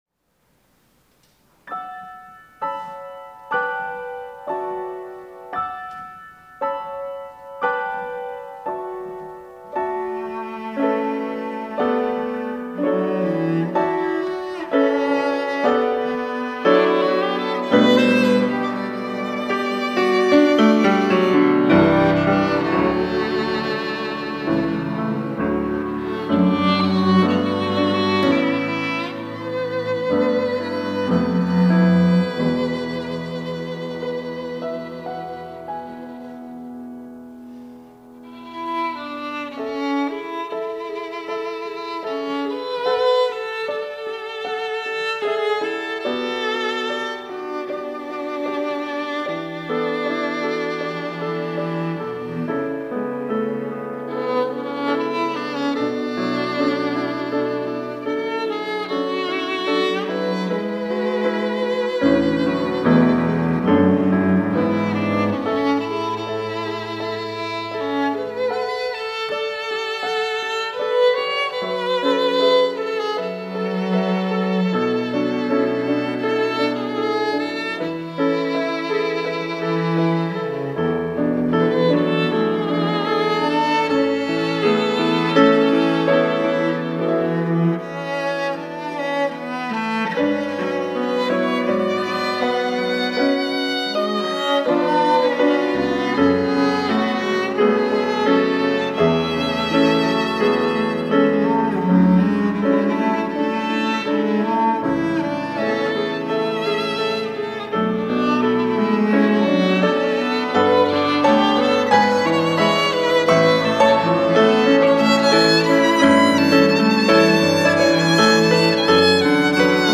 특송과 특주 - 축복하노라
청년부